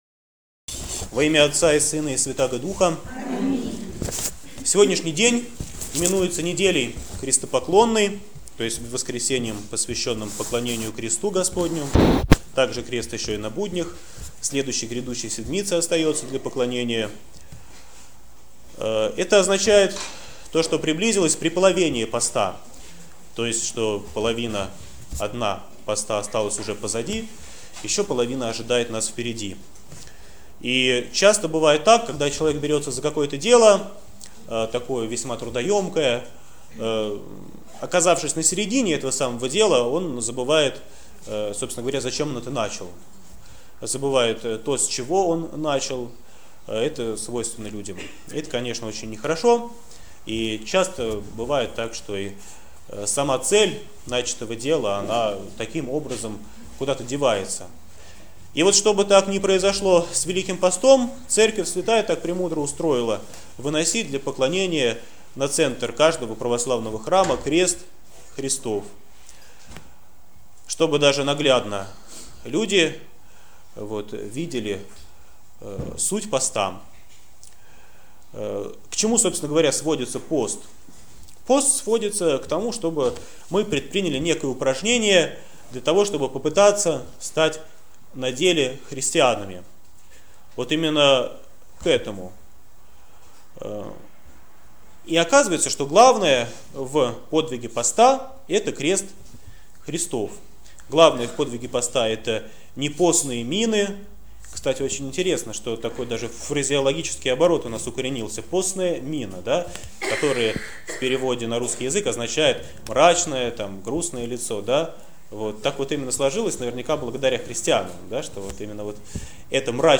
Проповедь в Неделю Крестопоклонную 2014